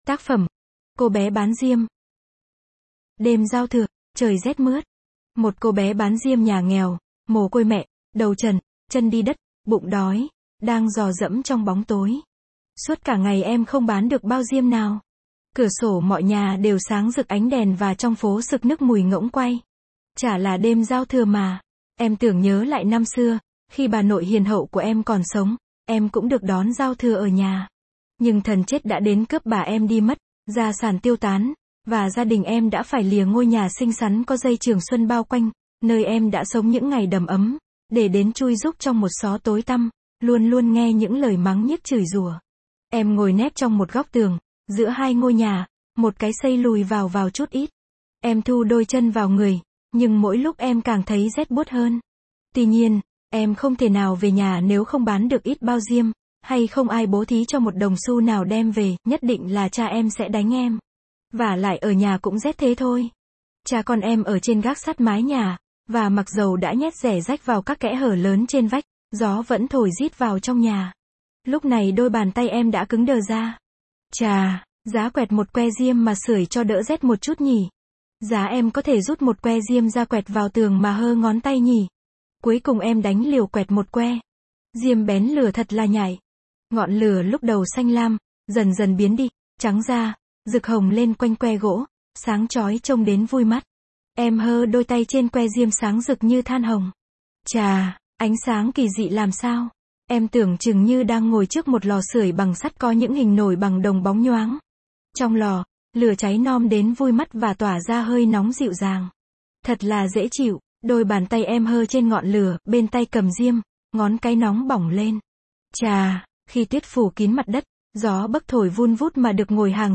Sách nói | CÔ BÉ BÁN DIÊM